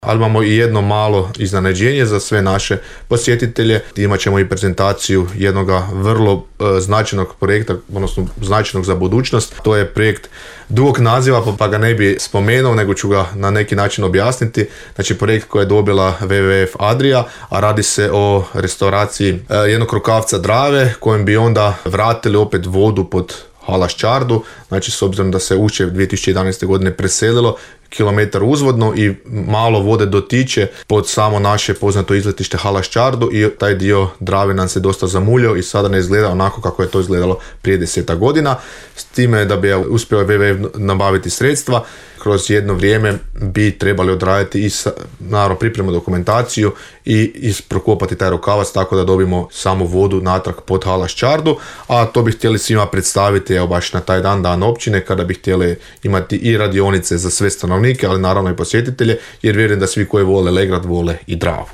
Potvrdio je to načelnik Ivan Sabolić za Podravski radio i spomenuo jedno iznenađenje koje organiziraju u sklopu proslave Dana Općine;